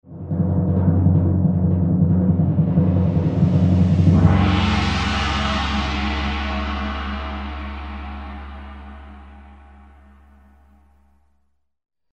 Дробь барабанов и гонг в финале